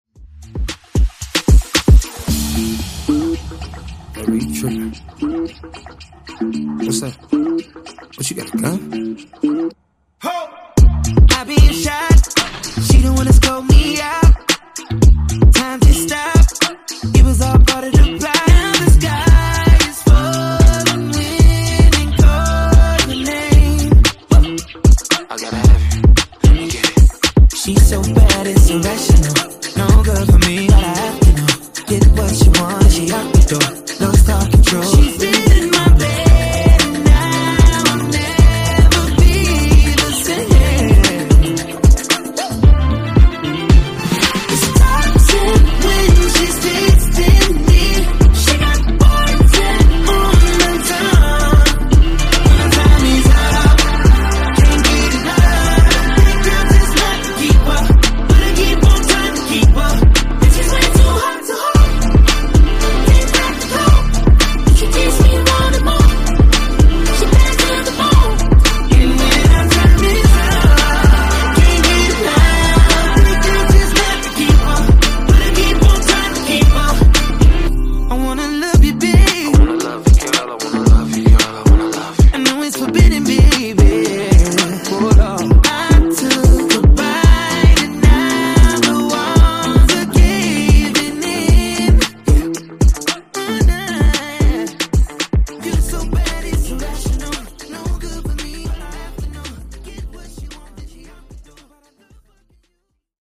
Genre: RE-DRUM Version: Clean BPM: 100 Time